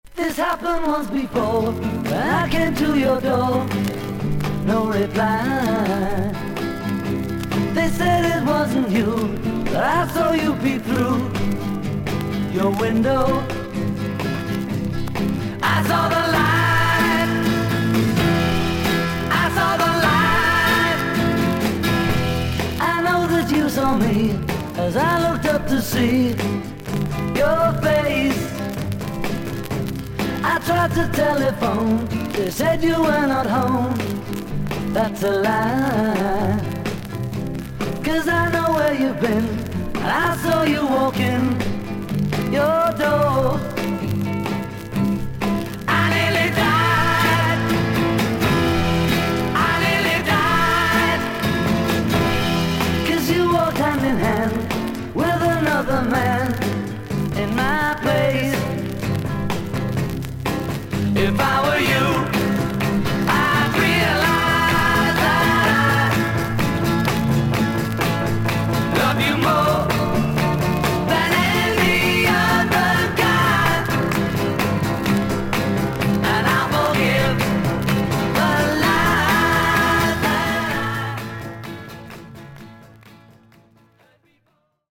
いくつか指に感じるキズもありますが、それほど大きなノイズはありません。
全体的にサーフィス・ノイズあり。